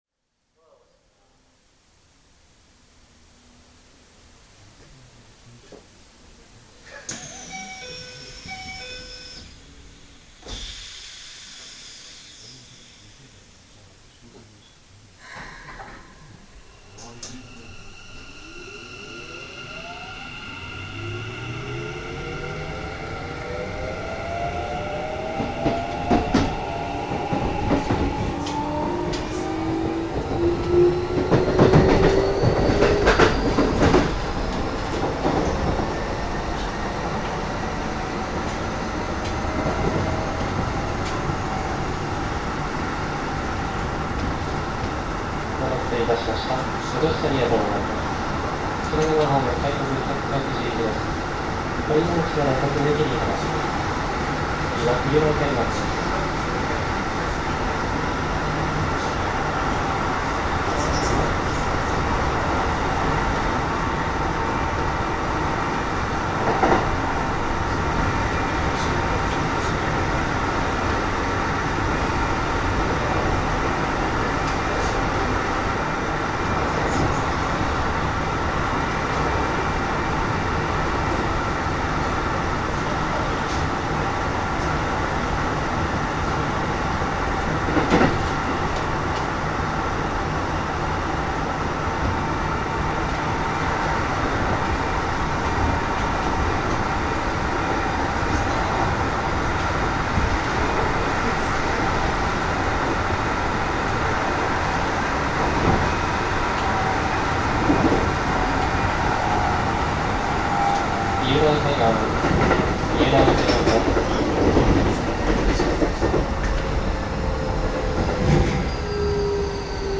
走行音 1079号の走行音です。三崎口〜三浦海岸間の走行音を公開中です。